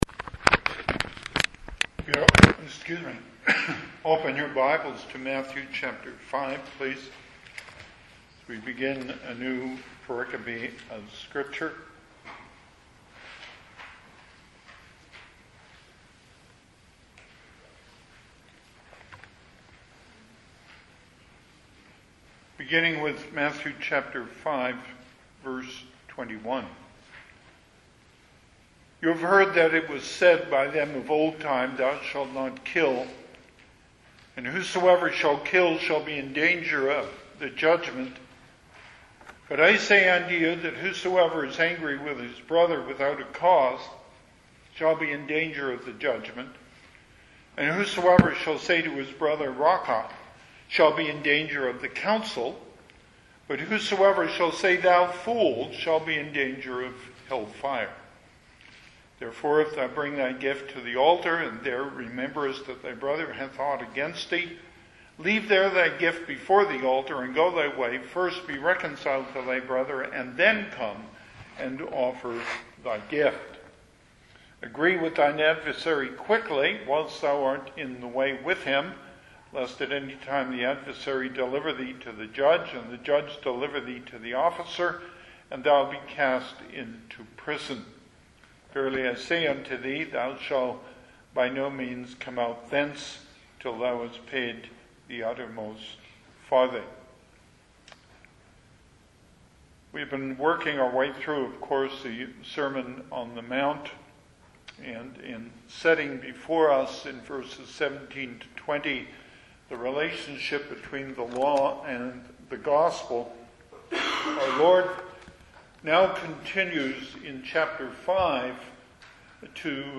Passage: Matthew 5:21-22 Service Type: Sunday AM